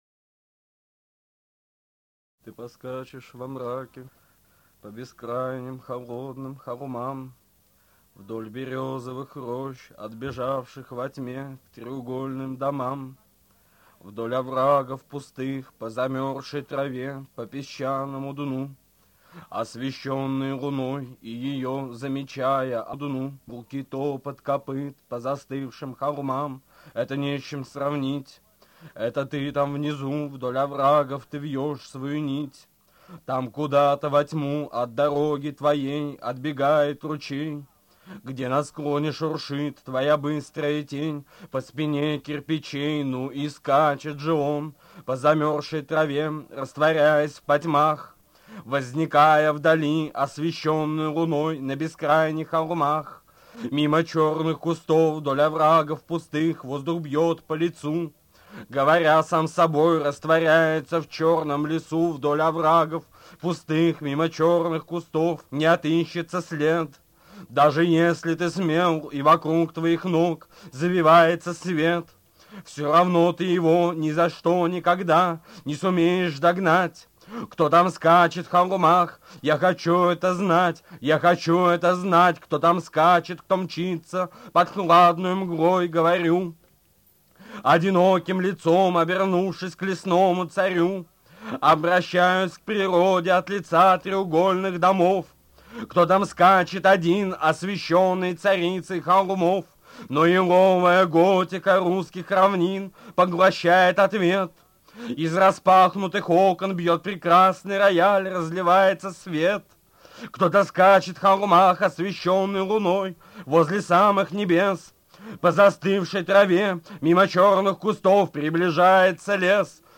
2. «Иосиф Бродский – Ты поскачешь во мраке, по бескрайним холодным холмам. (читает автор)» /
iosif-brodskij-ty-poskachesh-vo-mrake-po-beskrajnim-holodnym-holmam-chitaet-avtor